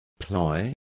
Complete with pronunciation of the translation of ploys.